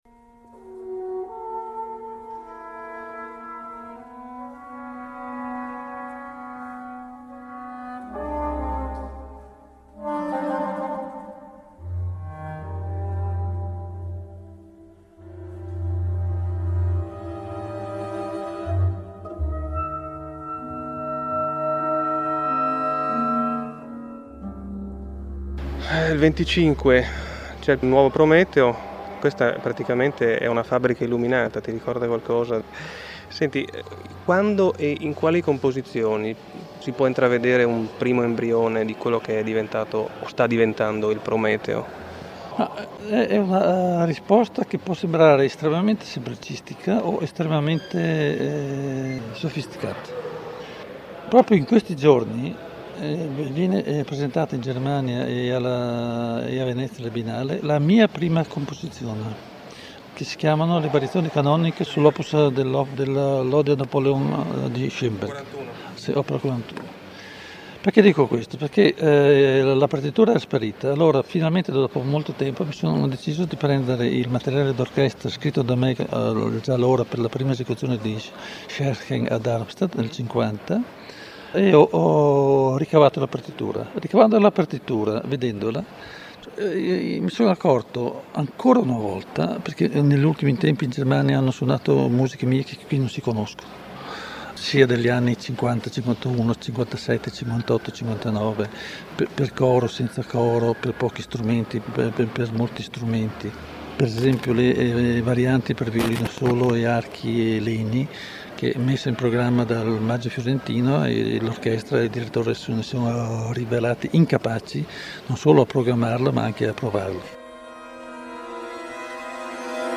Ero presente in entrambe le occasioni, l’incontro con Luigi Nono che qui ascoltiamo, fu in occasione di quest’ultima.